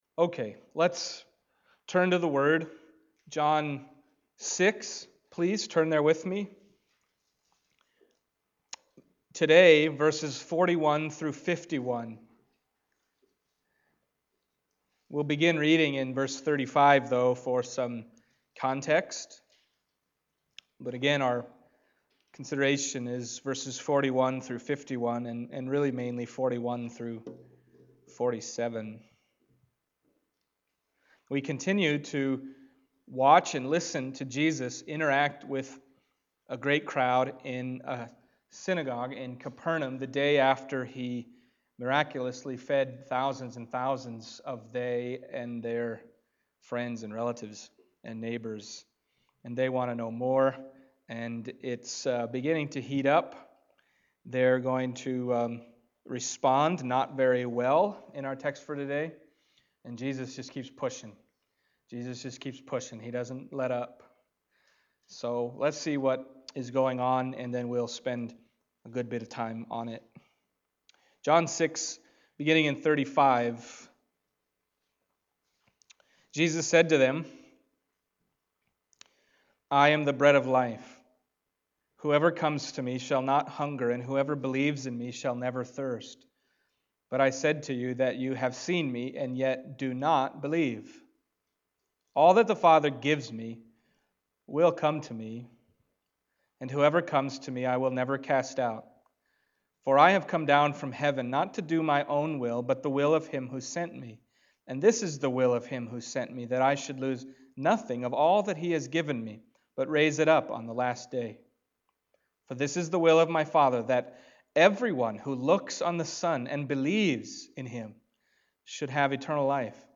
Passage: John 6:41-51 Service Type: Sunday Morning